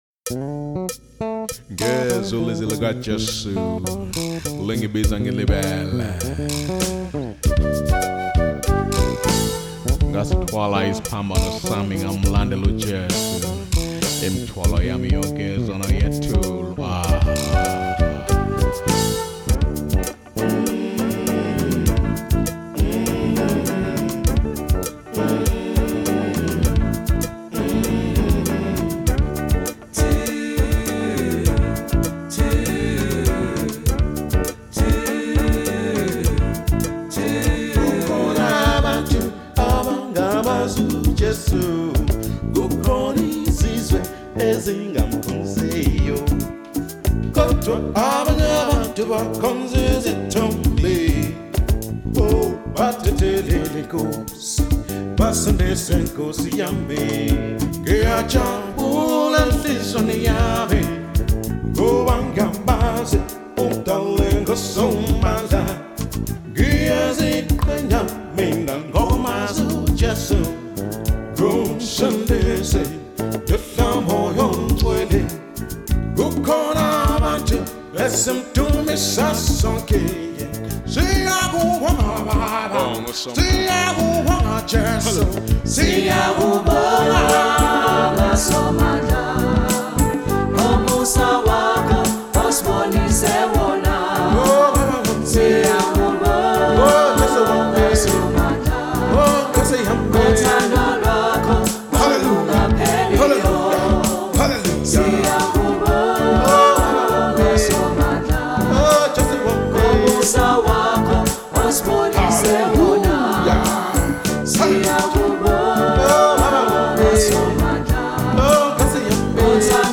up tempo and exuberant music